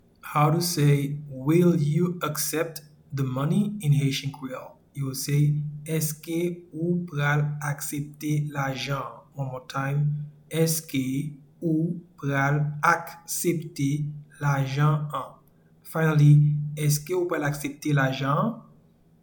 Pronunciation and Transcript:
Will-you-accept-the-money-in-Haitian-Creole-Eske-ou-pral-aksepte-lajan-an-1.mp3